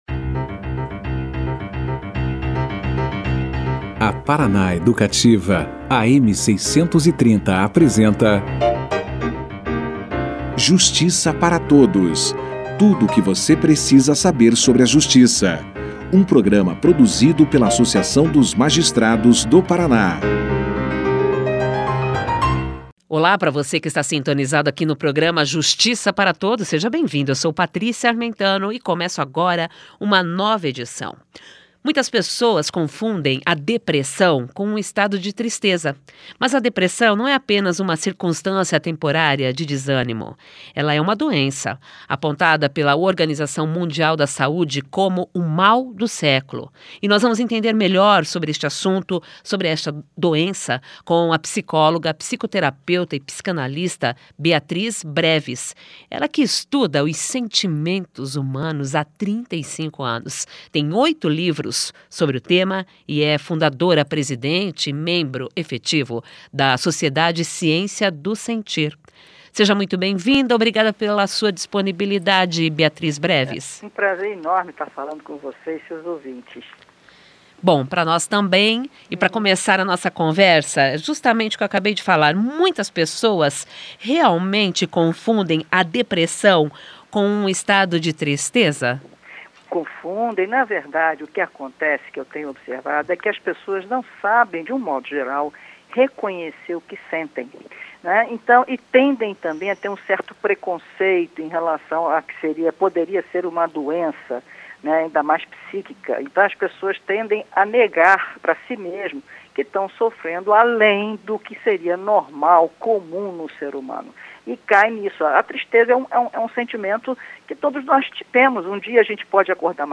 >>Clique Aqui e Confira a Entrevista na Integra<<